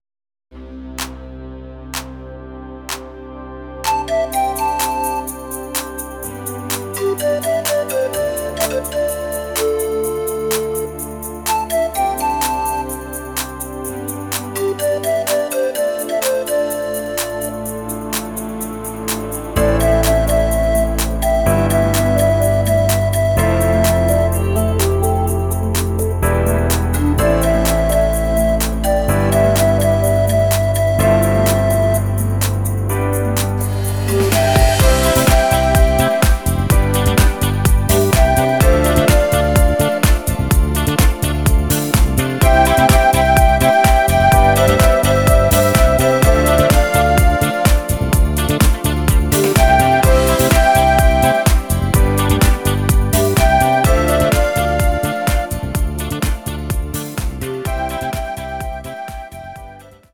(Disco Mix)